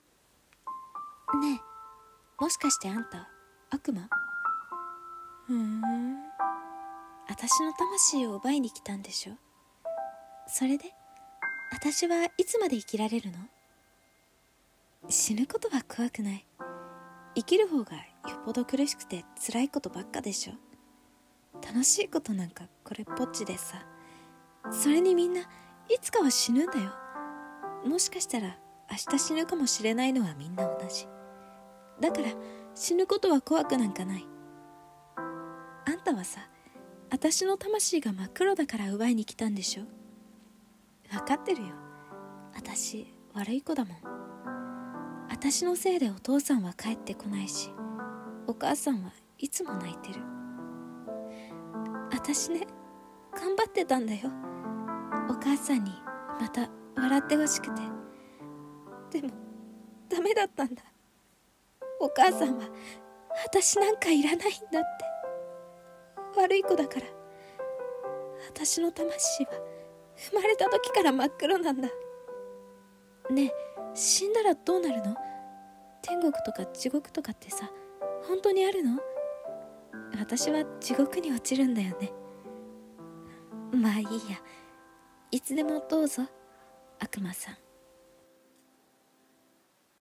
声劇「悪魔と少女」